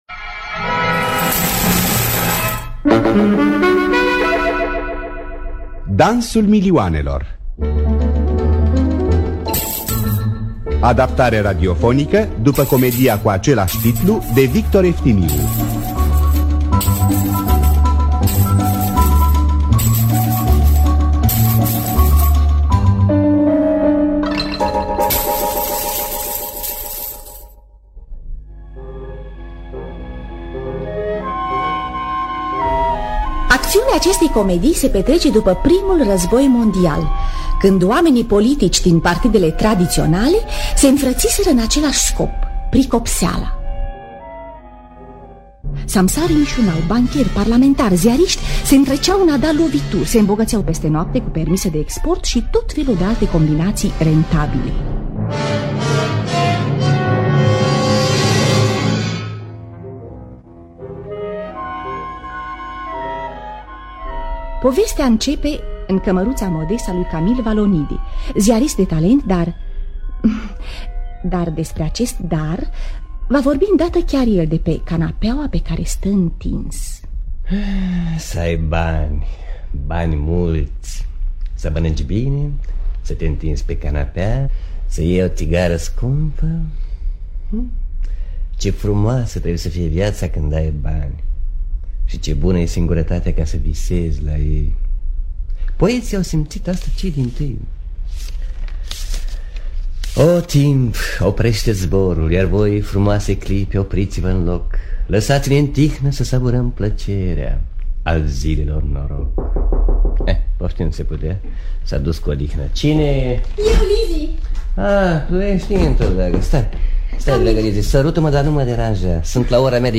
Victor Eftimiu – Dansul Milioanelor (1965) – Teatru Radiofonic Online